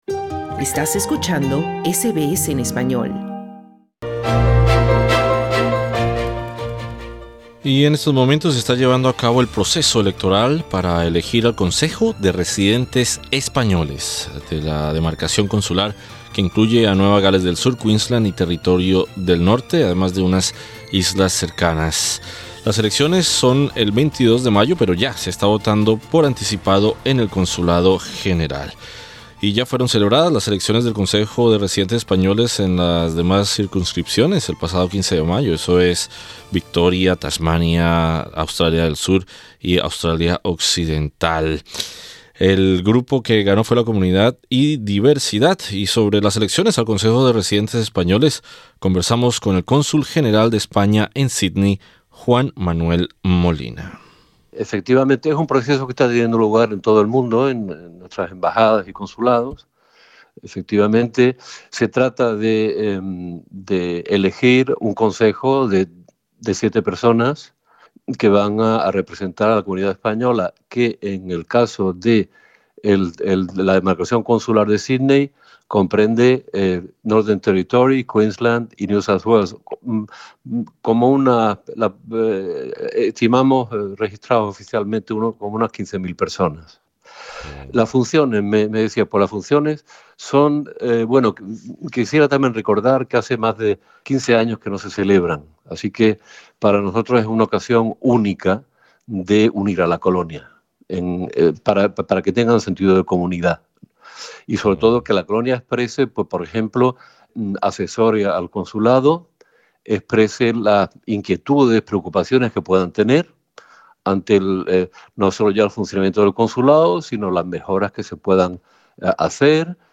Sobre las elecciones al Consejo de Residentes Españoles, conversamos con el Cónsul General de España en Sídney, Juan Manuel Molina. Escucha la entrevista haciendo clic en la imagen de portada.